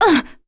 Seria-Vox_Damage_02.wav